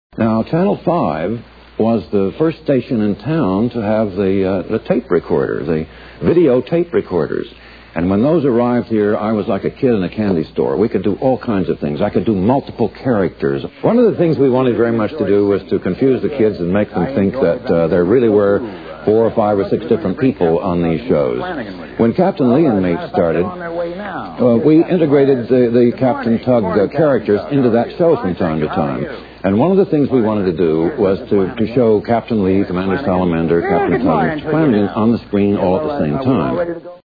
The original airdate of this klip is unknown, but it was extracted from a 1985 show commemorating the 40th Anniversary of WTTG-TV 5